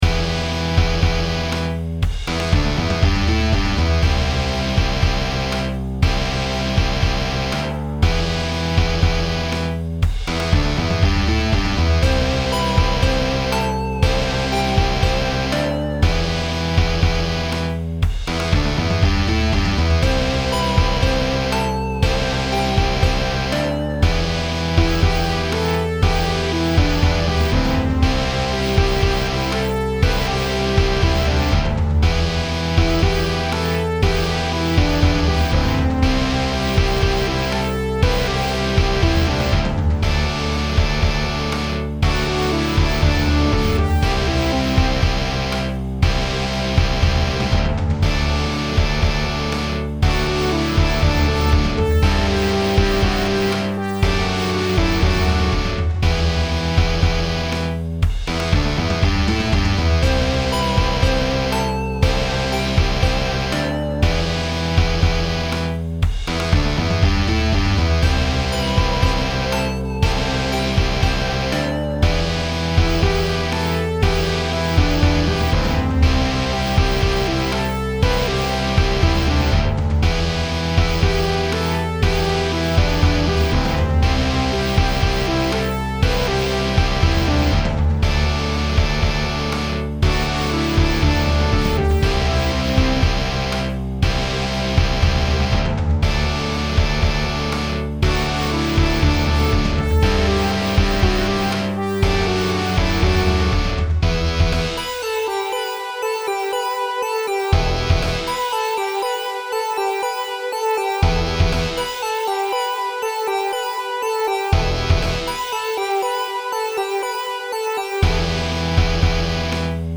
HardRock